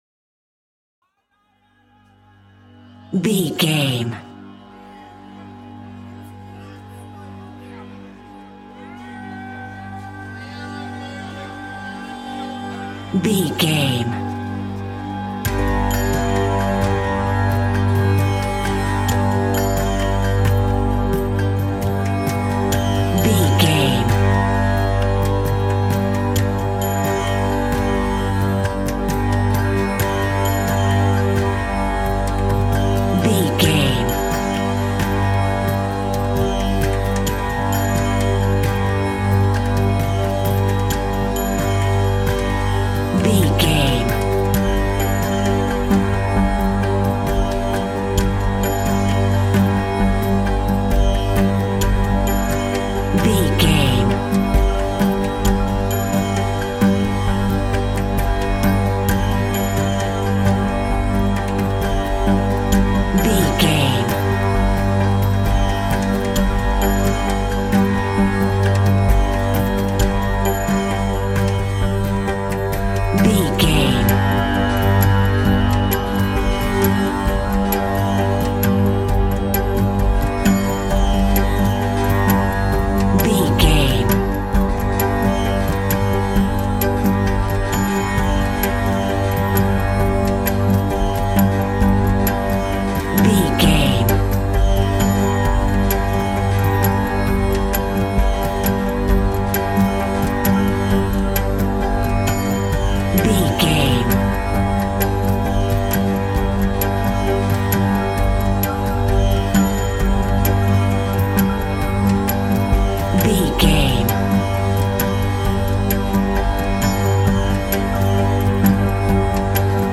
Ionian/Major
Slow
relaxed
tranquil
synthesiser
drum machine